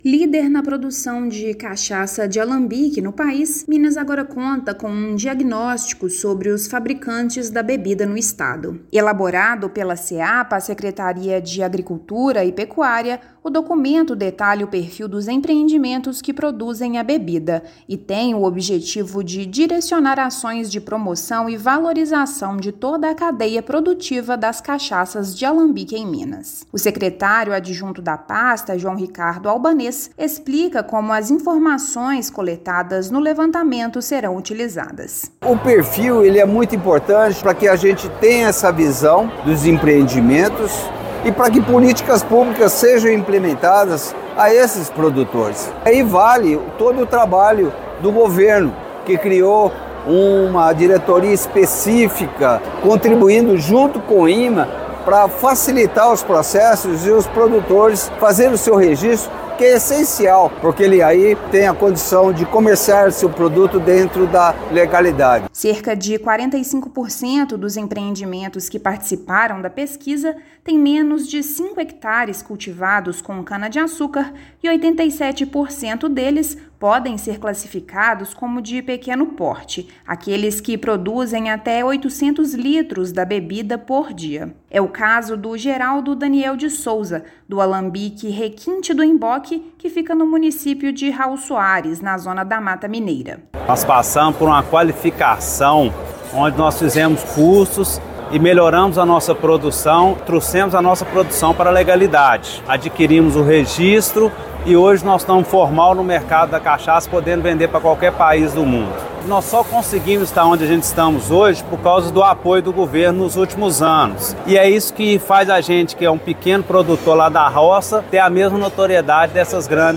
Diagnóstico realizado pelo Governo de Minas, por meio da Secretaria de Estado de Agricultura, Pecuária e Abastecimento (Seapa), mostra a predominância dos pequenos empreendimentos e presença significativa da mão de obra familiar na produção da cachaça artesanal de alambique. Ouça matéria de rádio.